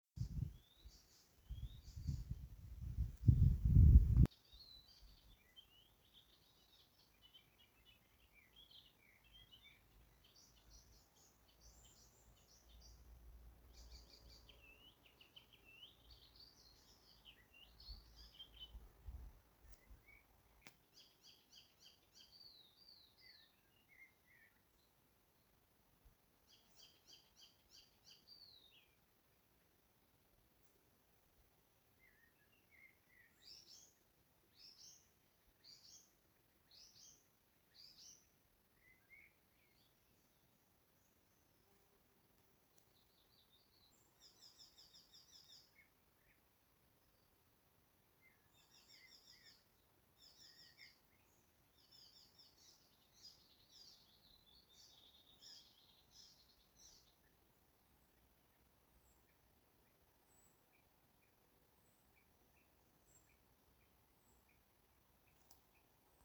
Hvilken fugl synger her?
Hørt syngende i skog/kratt i Skien.
N.N (02.06.2024) Svar: Gulsanger.